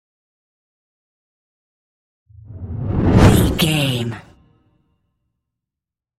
Whoosh deep fast
Sound Effects
Fast
dark
intense
whoosh